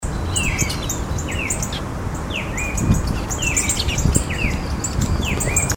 Golden-billed Saltator (Saltator aurantiirostris)
Por lo menos tres juntos y alguno vocalizando.
Location or protected area: Reserva Ecológica Costanera Sur (RECS)
Condition: Wild
Certainty: Recorded vocal